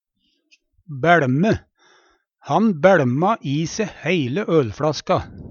DIALEKTORD PÅ NORMERT NORSK bæLme drikke fort og mykje Infinitiv Presens Preteritum Perfektum bæLme bæLma bæLma bæLma Eksempel på bruk Han bæLma i se heile fLaska.